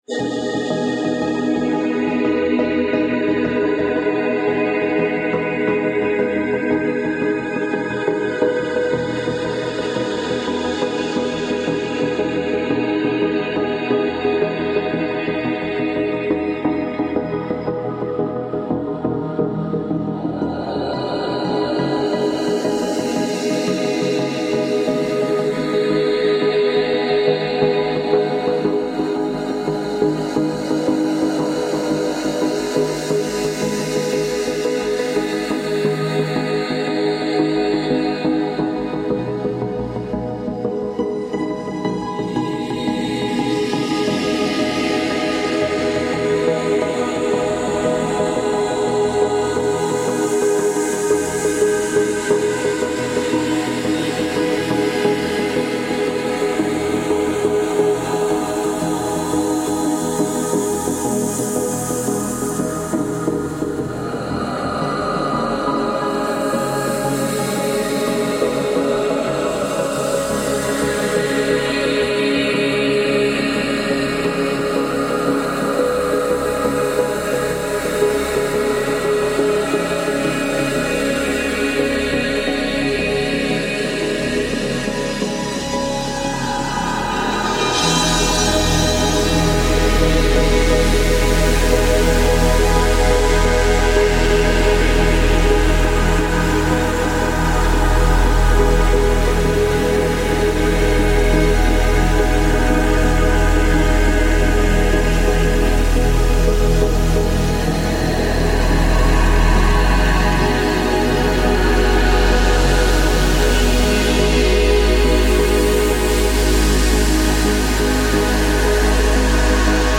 Music / Techno
ambient drum and bass